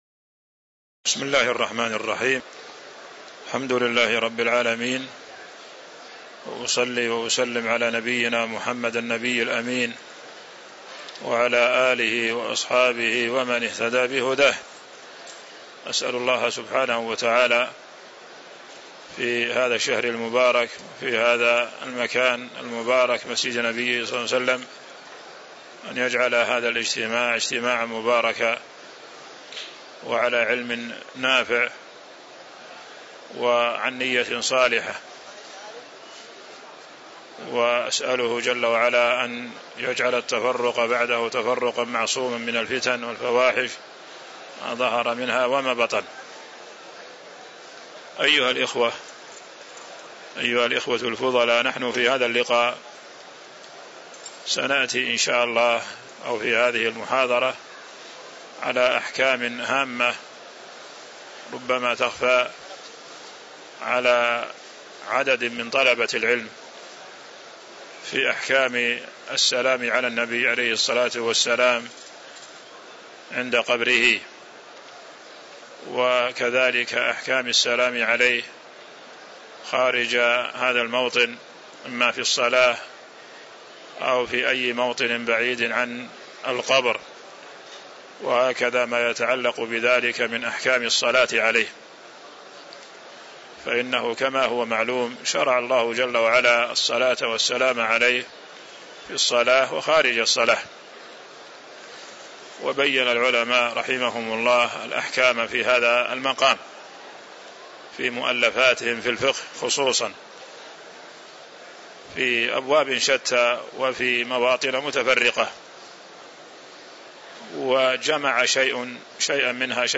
تاريخ النشر ٦ رمضان ١٤٤٤ هـ المكان: المسجد النبوي الشيخ